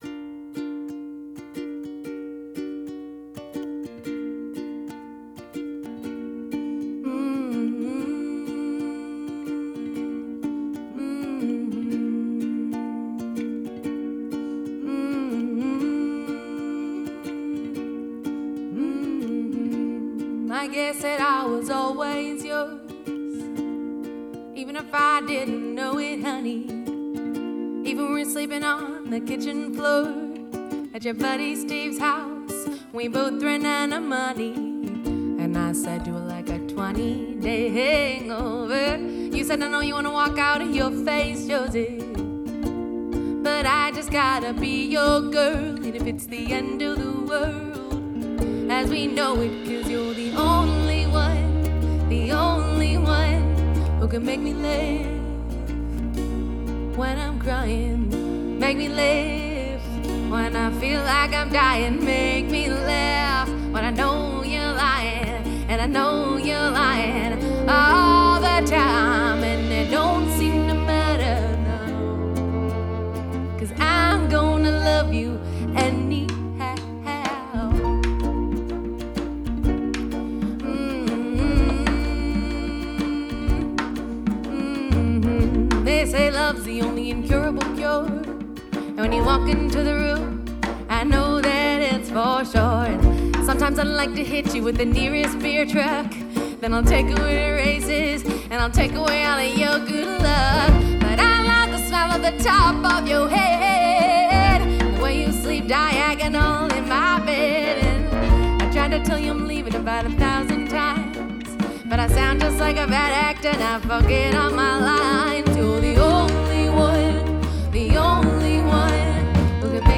We believe in presenting artists exactly as they perform.
They’re kinda like a Bluegrass Band with a horn section.